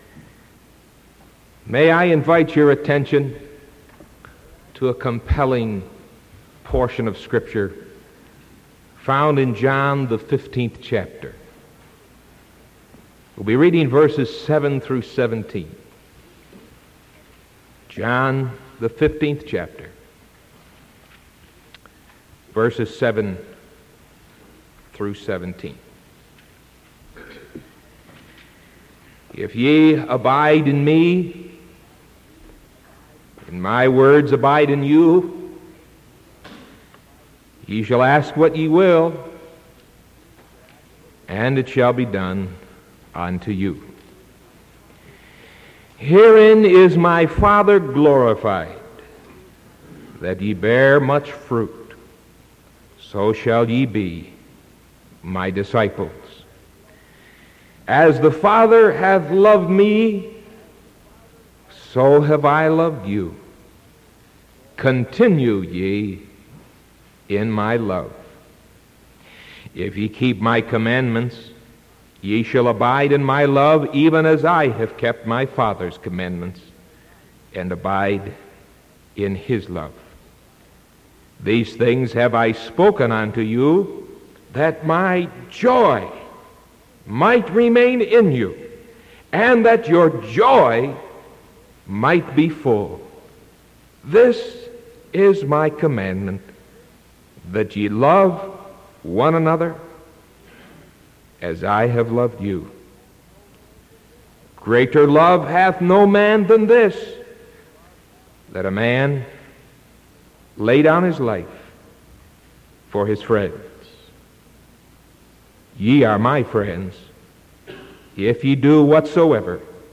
Sermon July 6th 1975 PM